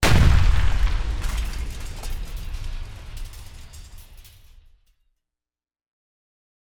OG SoundFX - Boom - Big Echoing Explosion Light Debris Falling.wav